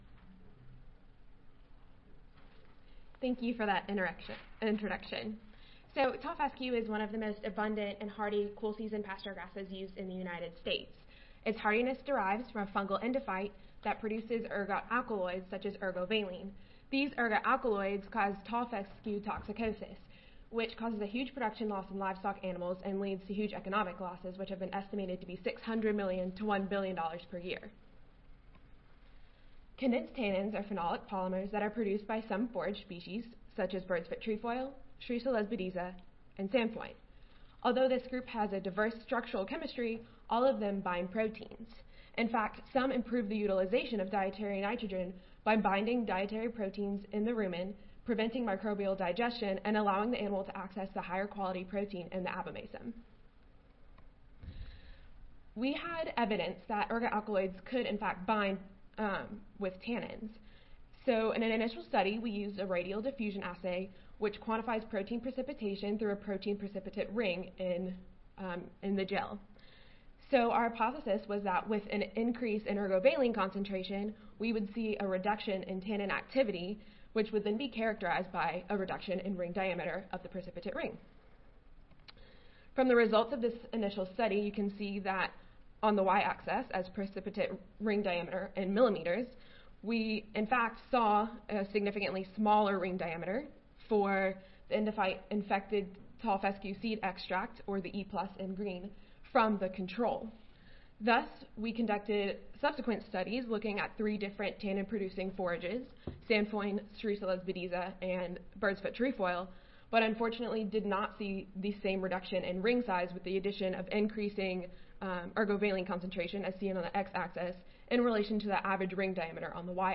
See more from this Division: Students of Agronomy, Soils and Environmental Sciences (SASES) See more from this Session: Symposium--Undergraduate Research Symposium Contest - Oral I